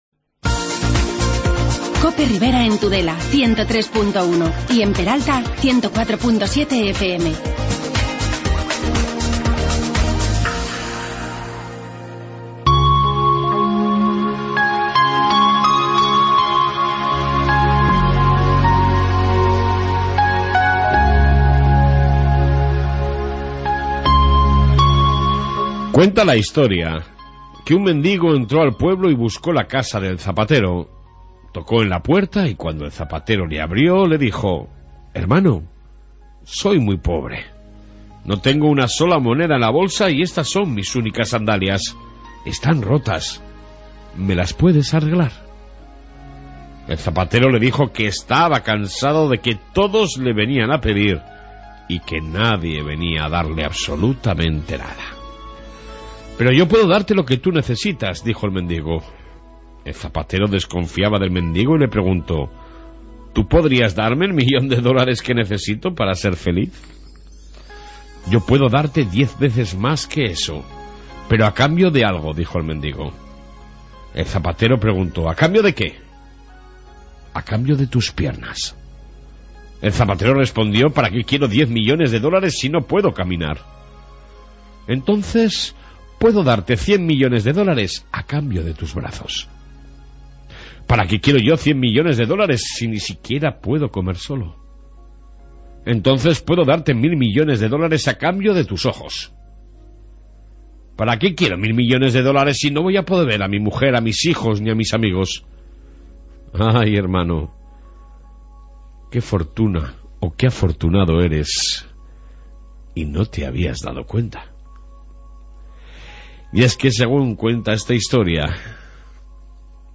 Reflexión diaria + Entrevista
Informe Policia Municipal